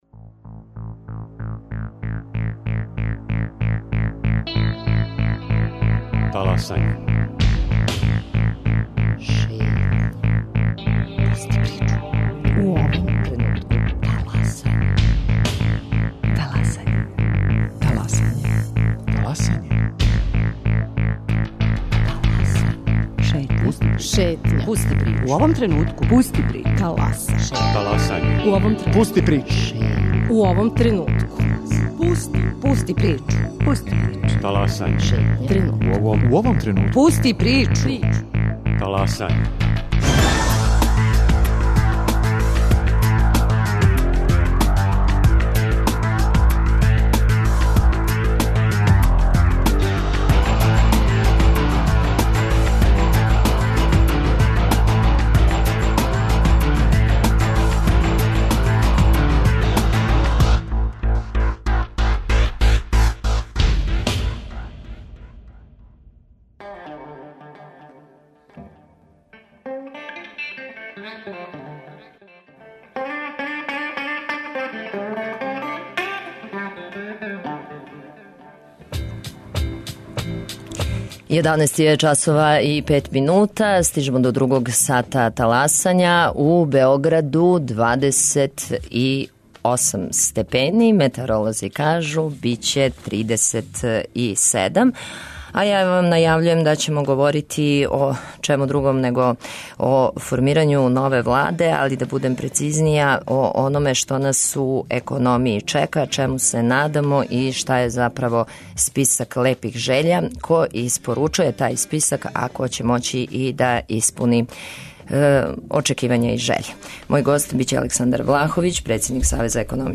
Гост Таласања Александар Влаховић, председник Савеза економиста Србије и бивши министар за привреду и приватизацију.